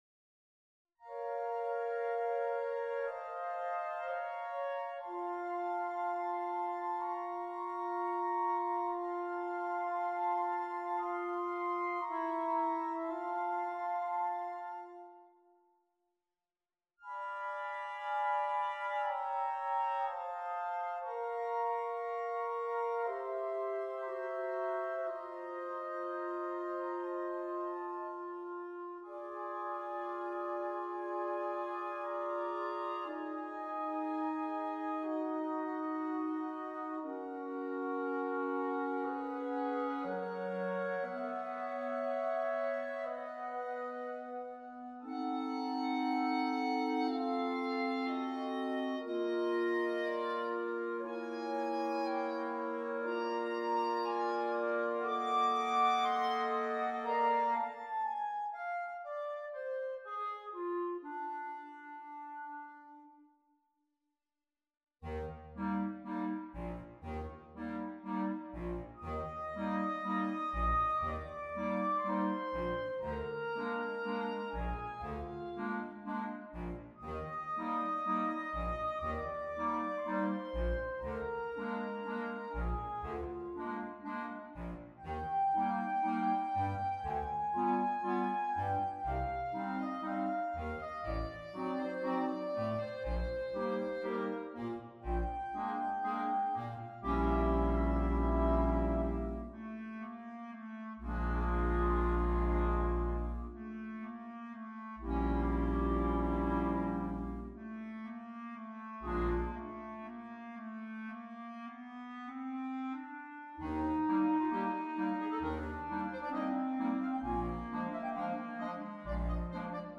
per coro di clarinetti